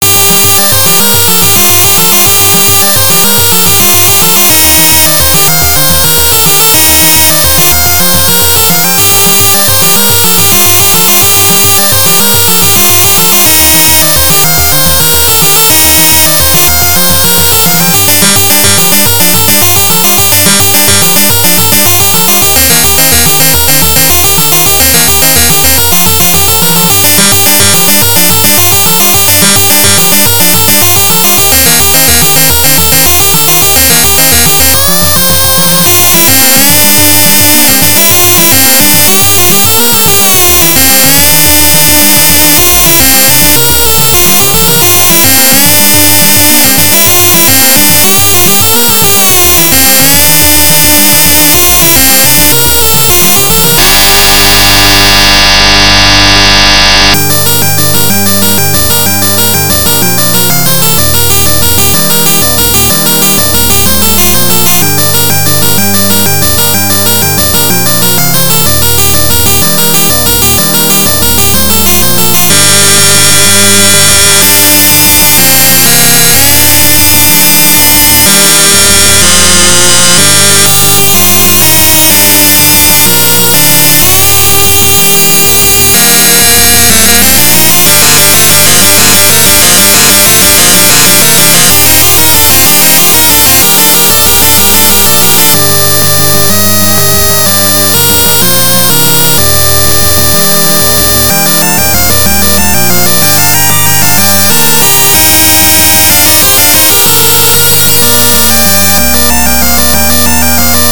why is the mixing like that